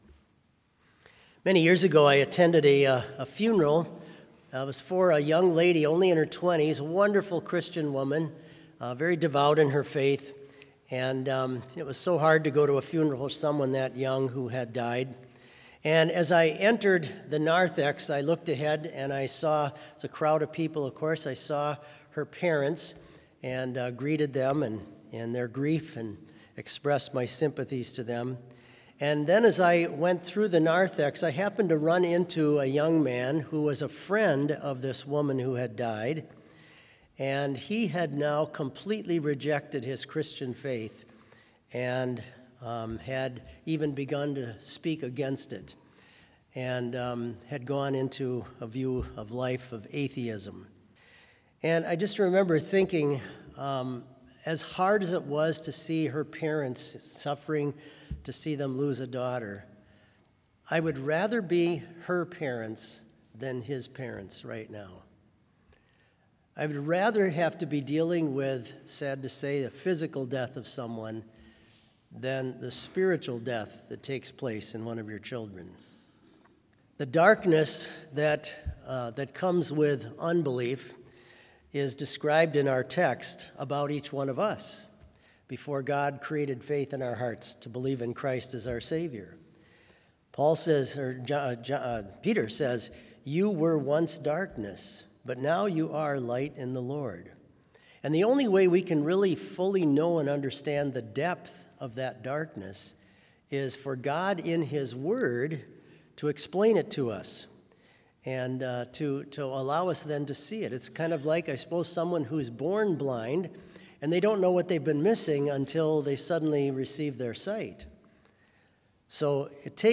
Complete Service
This Chapel Service was held in Trinity Chapel at Bethany Lutheran College on Friday, November 7, 2025, at 10 a.m. Page and hymn numbers are from the Evangelical Lutheran Hymnary.